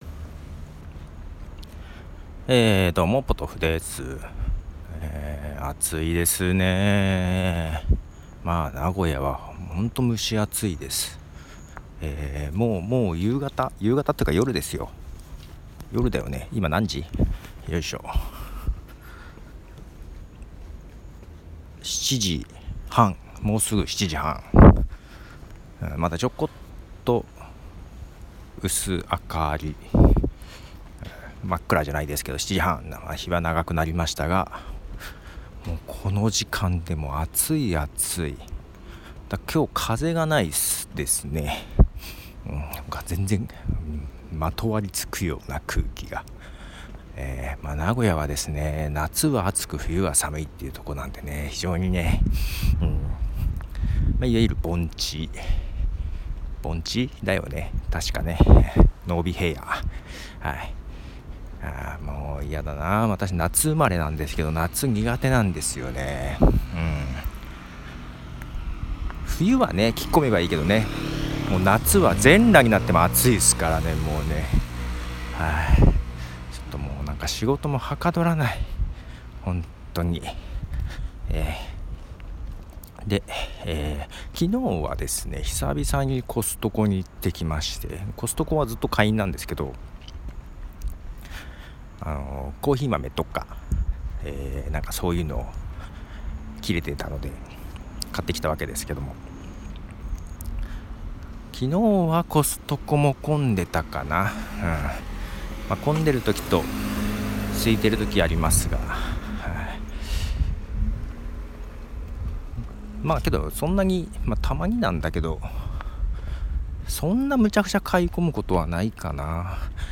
いつも以上にダラダラと喋ってます